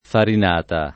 farinata [ farin # ta ] s. f.